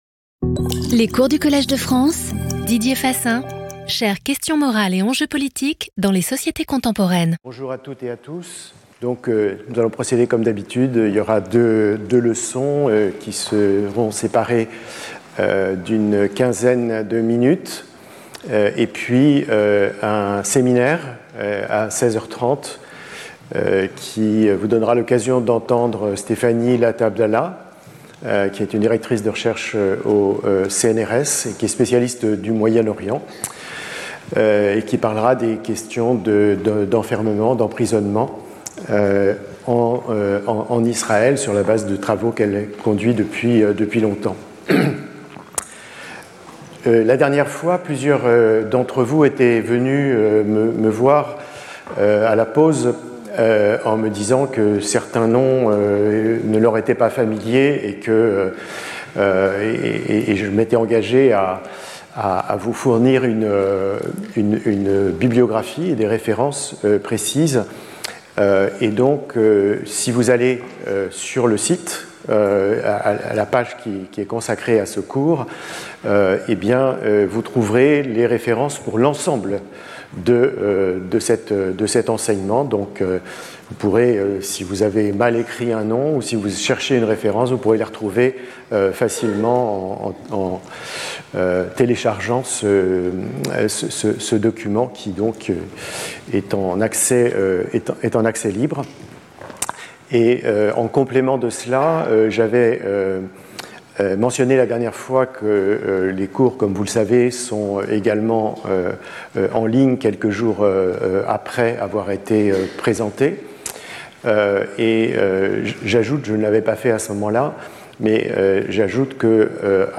Speaker(s) Didier Fassin Professor at the Collège de France
Lecture